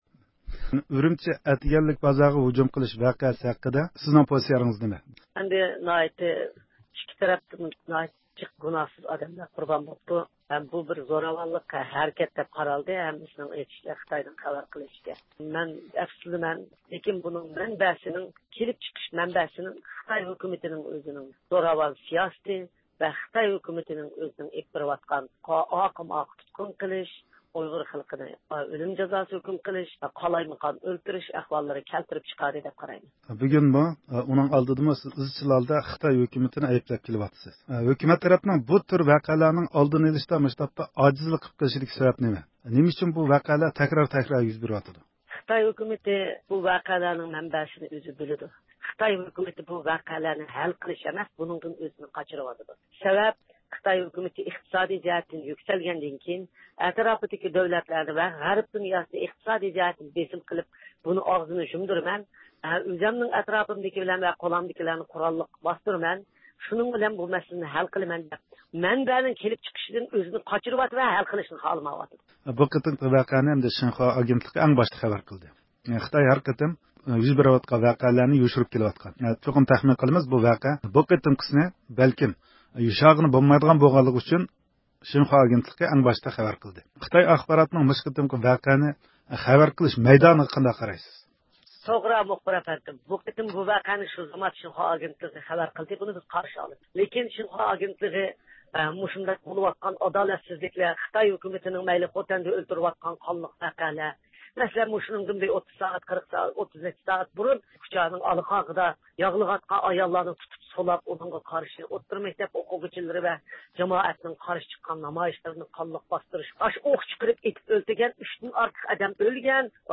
ھۆرمەتلىك رادىئو ئاڭلىغۇچىلار، رابىيە خانىم سۆزىنىڭ ئاخىرىدا ئامېرىكا قاتارلىق دۆلەتلەرنىڭ مەزكۇر ۋەقەگە قارىتا بەرگەن باياناتلىرى ئۈستىدە توختىلىپ ۋەقەنىڭ ئۆزىنىلا ئەمەس، ئۇنى كەلتۈرۈپ چىقارغان ۋەزىيەتنىمۇ كۆزىتىشكە چاقىردى.